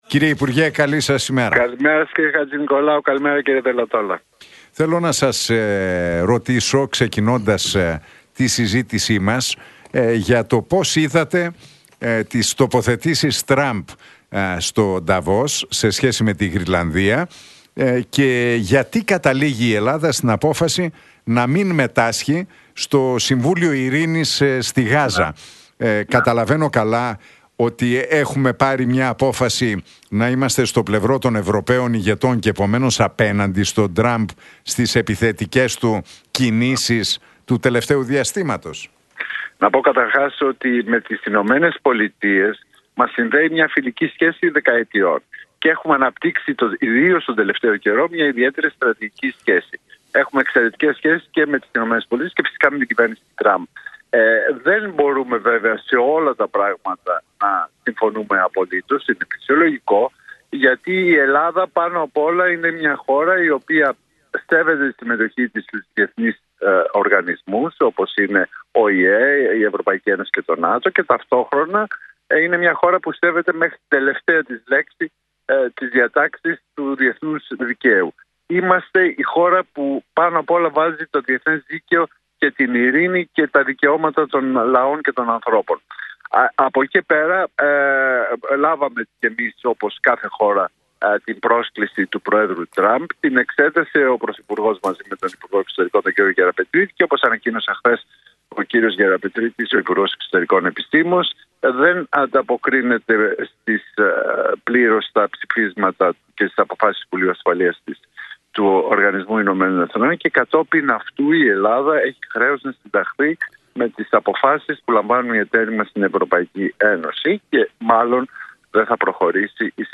Για τις σχέσεις της Ελλάδας με τις ΗΠΑ και την κυβέρνηση Τραμπ και την εσωτερική πολιτική επικαιρότητα μίλησε ο υφυπουργός Εξωτερικών, Γιάννης Λοβέρδος στον